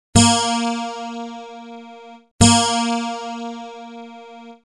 Pst... Klicka på tonerna så kan du stämma din gitarr efter ljudet!